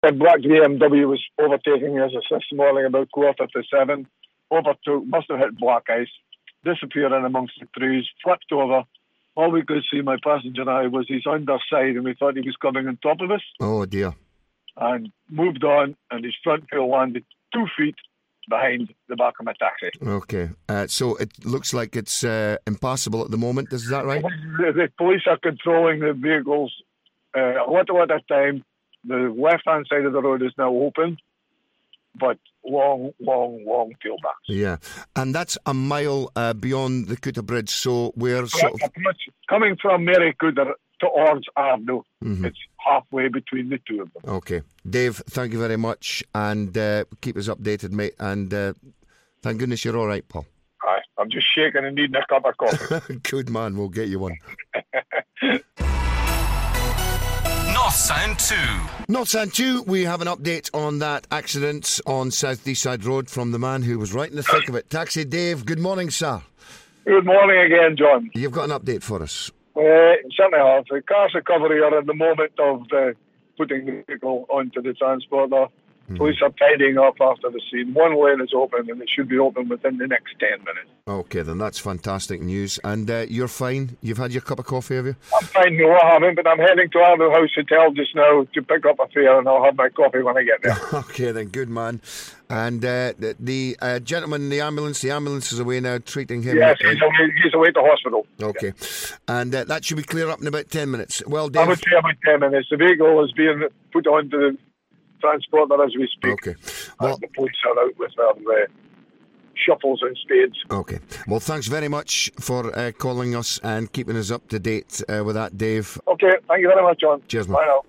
Eyewitness account of a one car accident on South Deeside Road, Aberdeenshire on 28th February.